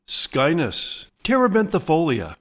Pronounciation:
SKY-nus te-re-bin-thi-FOL-e-a